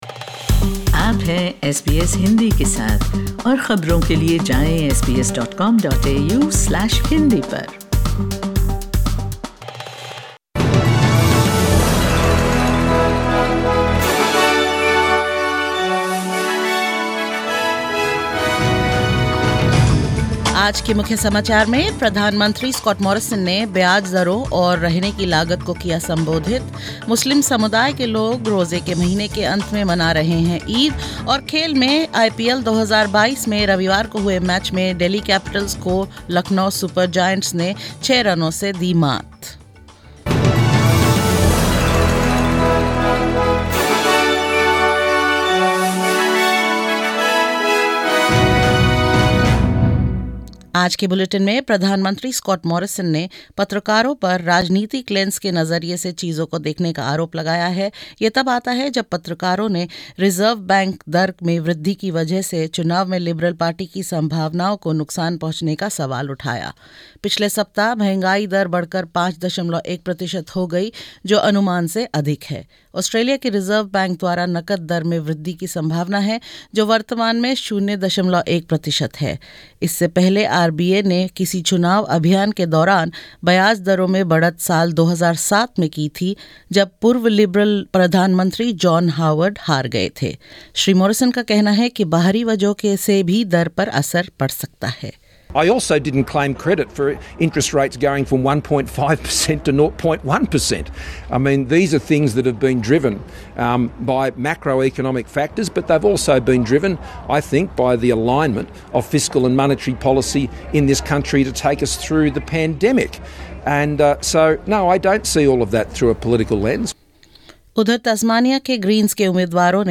In this SBS Hindi bulletin: Prime Minister Scott Morrison attempts to depoliticise potential interest rate hike; Muslims gather to celebrate Eid and pray at the end of the holy month of Ramadan; In IPL 2022 Lucknow Super Giants move to the second spot in the points table after beating Delhi Capitals and more.